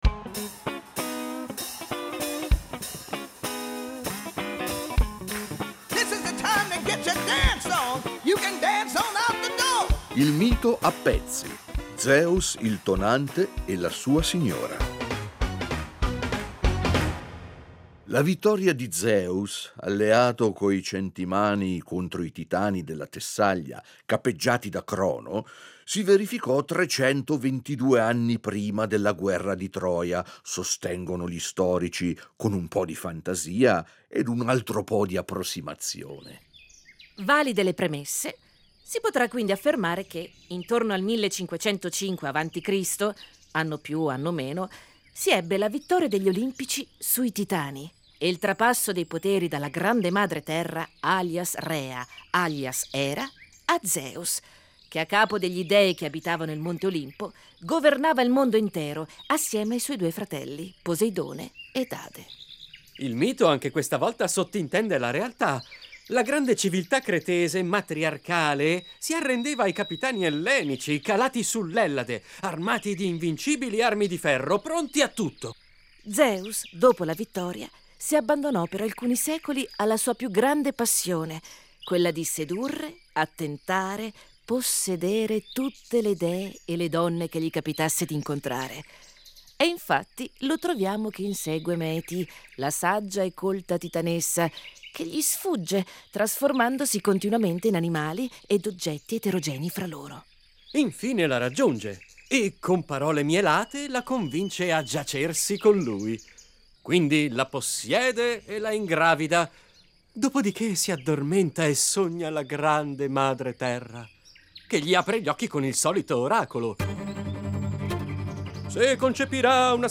con tono leggero, ma preciso